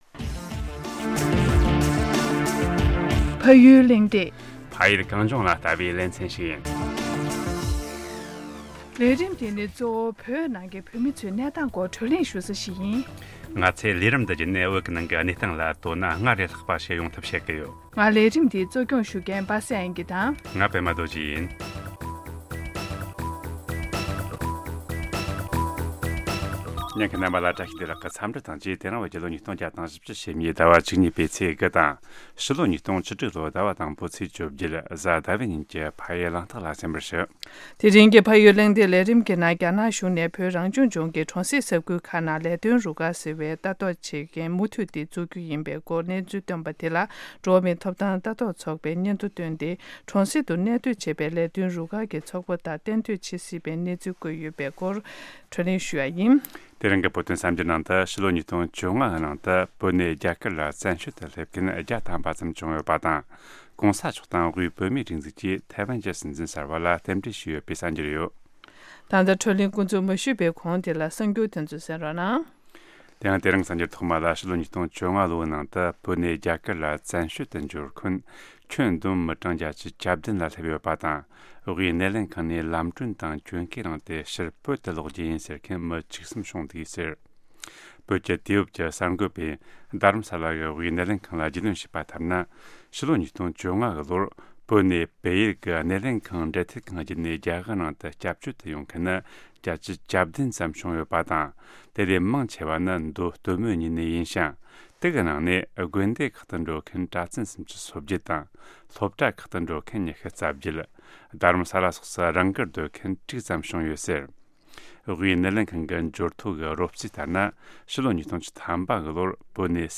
གདན་འདྲེན་ཞུས་ཏེ་བགྲོ་གླེང་བྱས་ཡོད།།